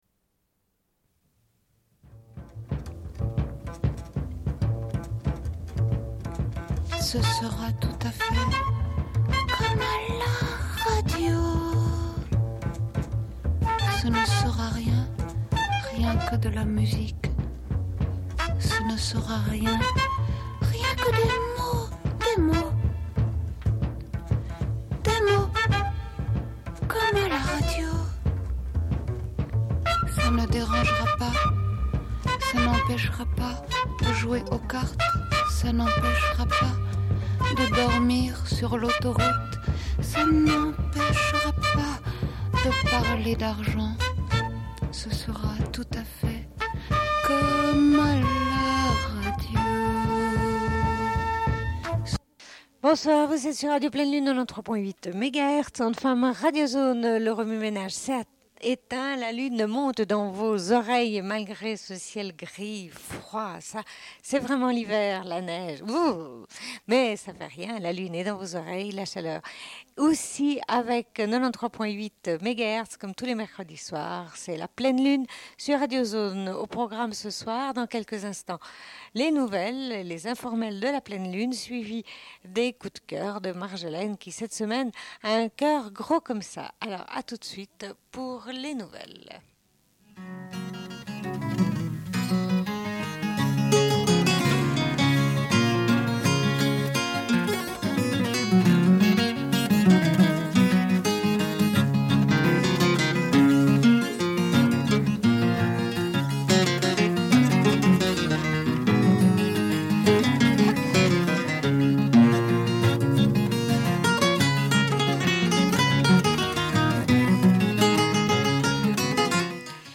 Bulletin d'information de Radio Pleine Lune du 20.11.1996 - Archives contestataires
Une cassette audio, face B